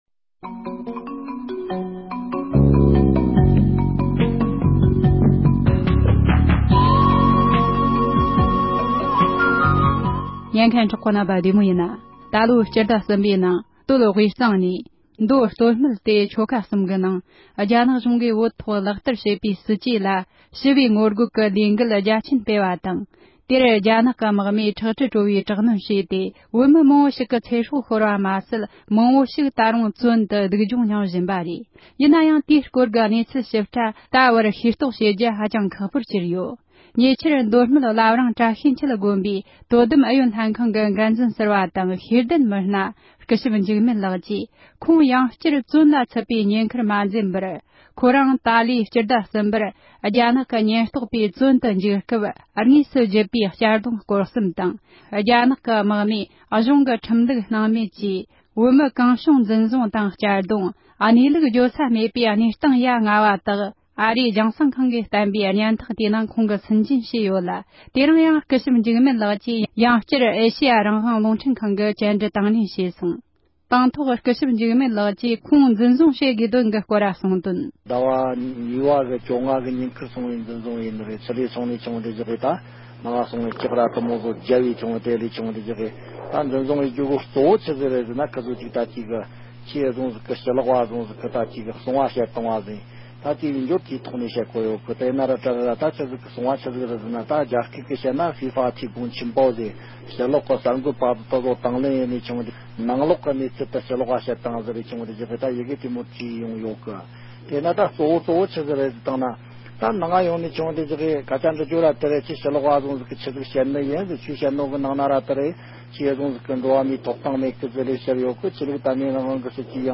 བོད་ནང་ཞི་བའི་ངོ་རྒོལ་གྱི་སྐབས་རྒྱ་ནག་གཞུང་གིས་འཛིན་བཟུང་བཙོན་འཇུག་བྱས་ཡོད་པའི་དགེ་འདུན་པ་ཞིག་གིས་ཁོང་འཛིན་བཟུང་བཙོན་འཇུག་བྱེད་དོན་སྐོར་གསུངས་བ།
སྒྲ་ལྡན་གསར་འགྱུར། སྒྲ་ཕབ་ལེན།